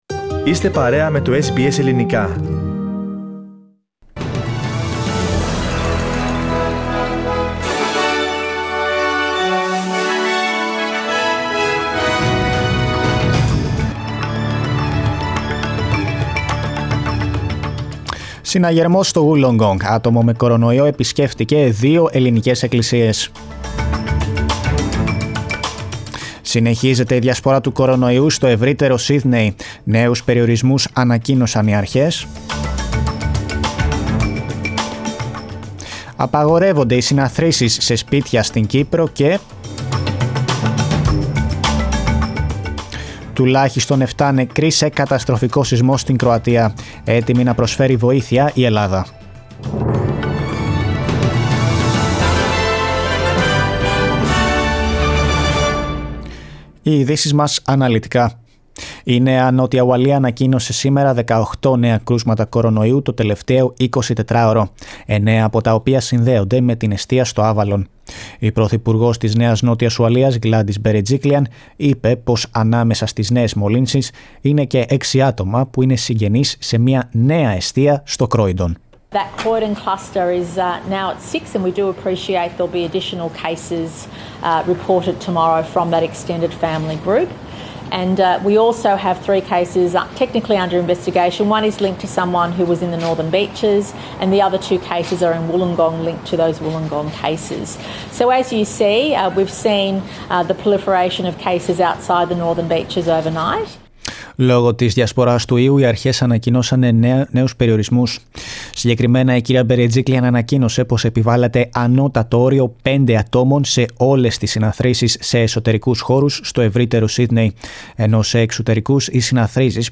News from Australia, Greece, Cyprus and the world in the news bulletin of Wendesday 30 of December.